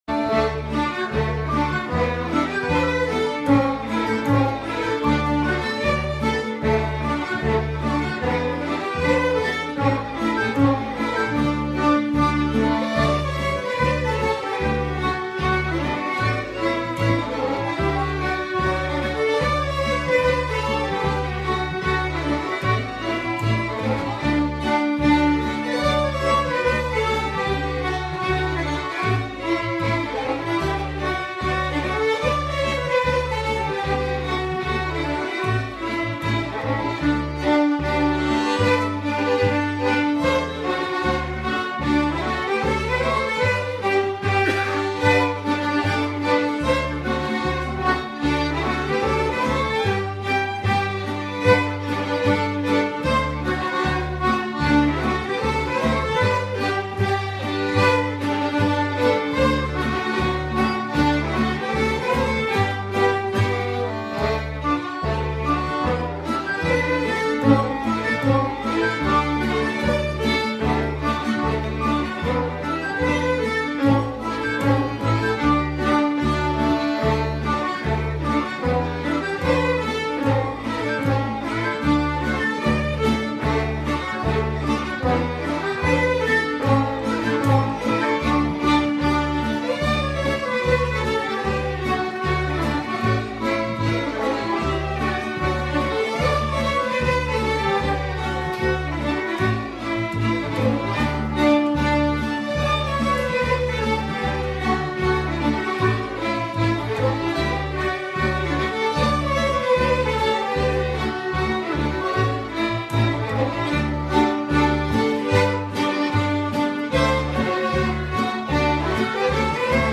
Musikken p� �lejren p� Ly�